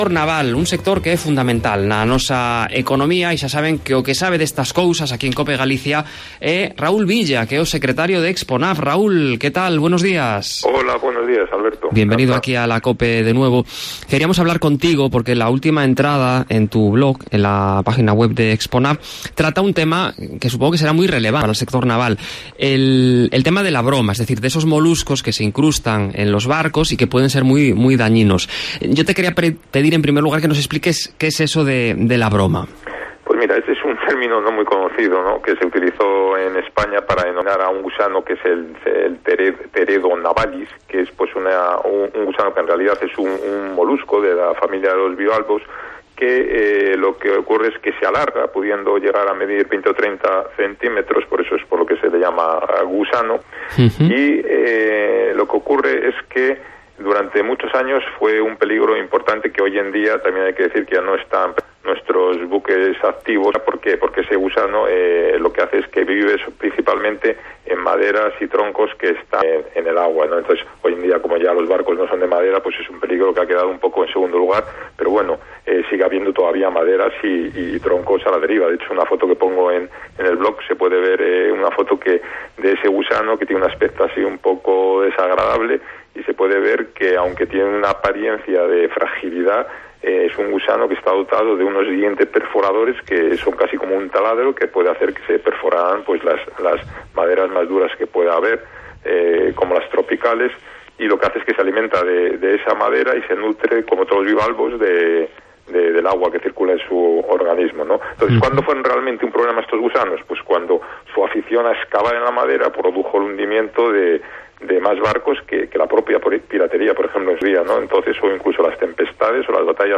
Hoy hemos hablado en Cope Galicia de la "broma", un molusco con forma de gusano que se alimenta de madera y que en su día fue muy peligroso para los barcos.